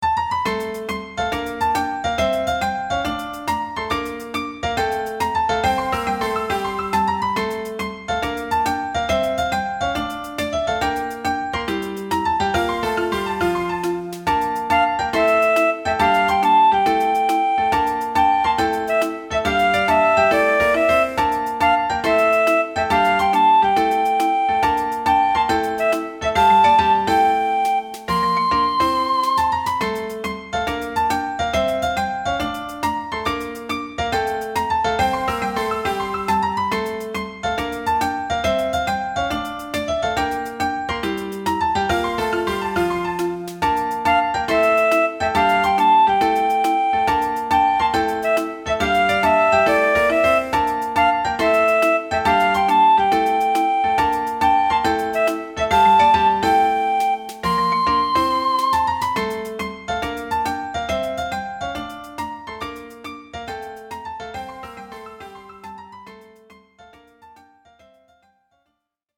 マリンバのころころした感じがイイ感じ。